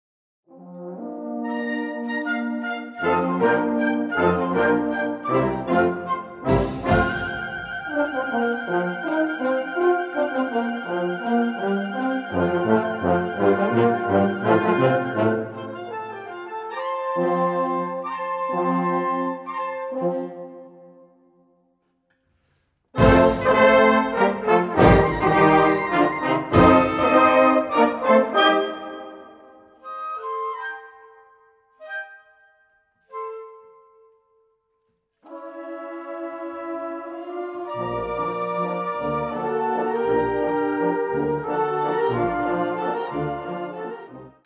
Gattung: Konzertwalzer
Besetzung: Blasorchester